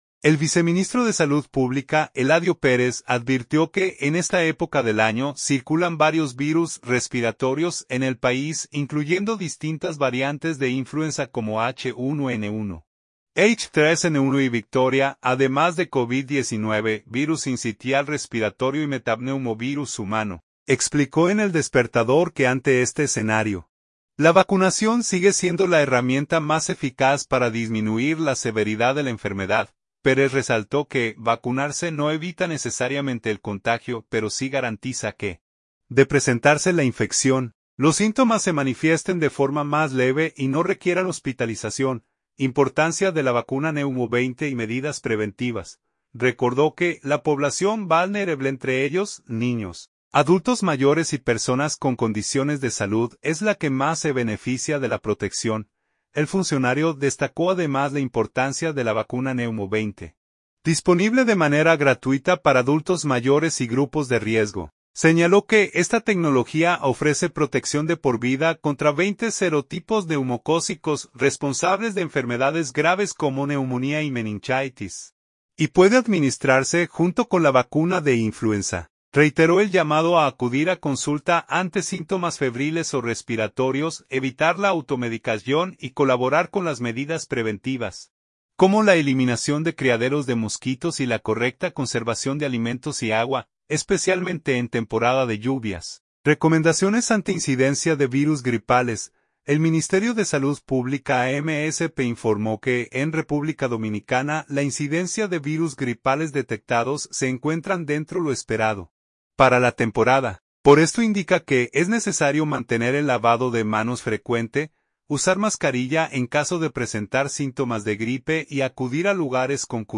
Explicó en El Despertador que ante este escenario, la vacunación sigue siendo la herramienta más eficaz para disminuir la severidad de la enfermedad.